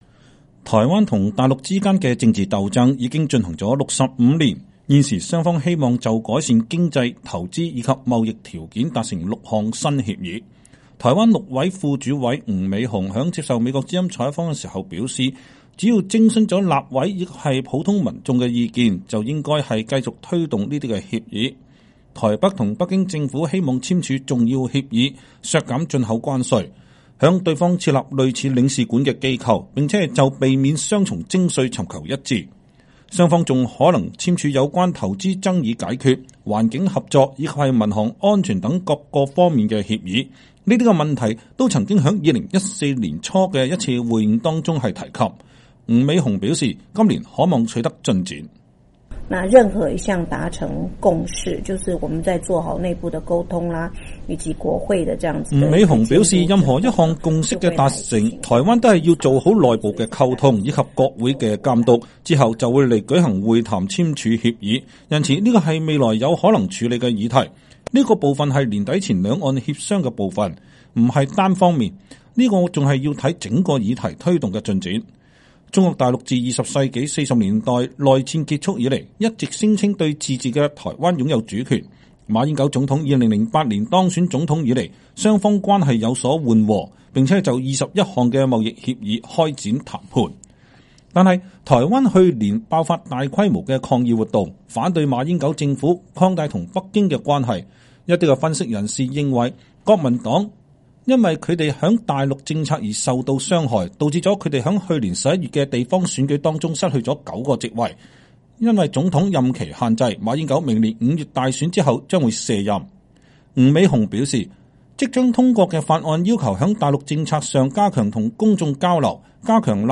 台灣陸委副主委吳美紅在接受美國之音採訪時說，只要徵詢了立委和普通民眾的意見，就應當繼續推動這些協議。